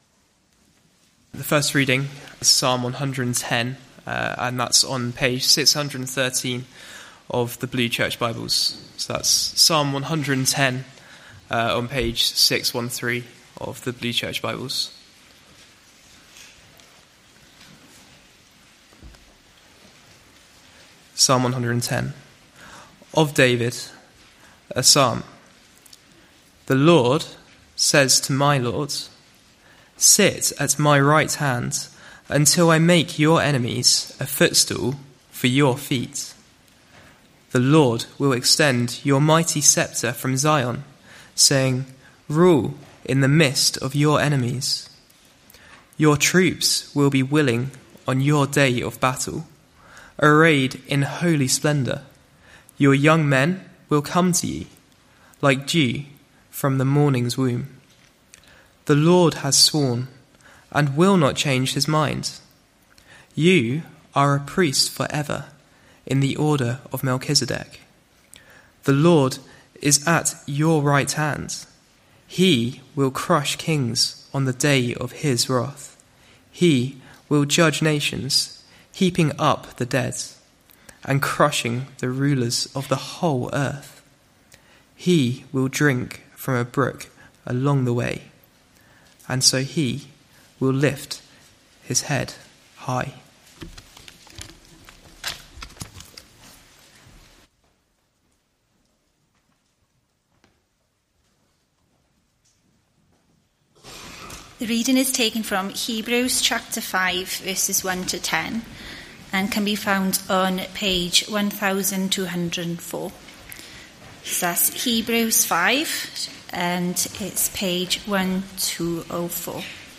Evening Service
Sermon